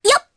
Reina-Vox_Jump_jp.wav